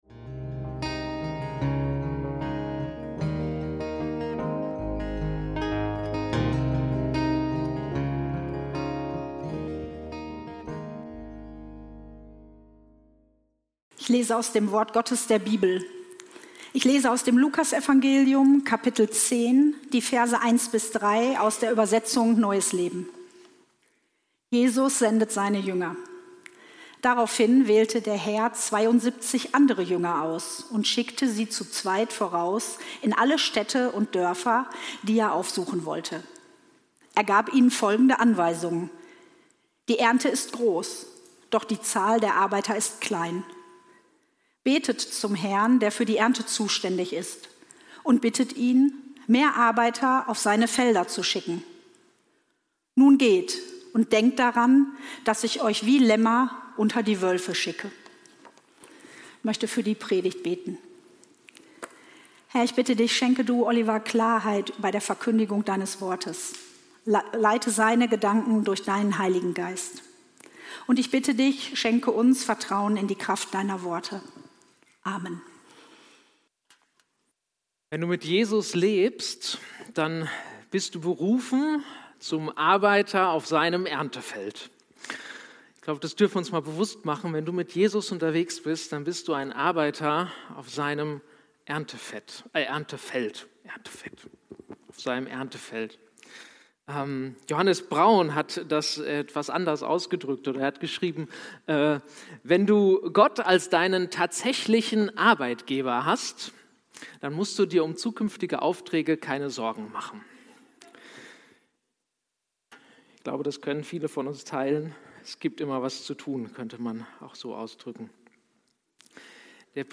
Silber und Gold habe ich nicht ... - Predigt vom 07.09.2025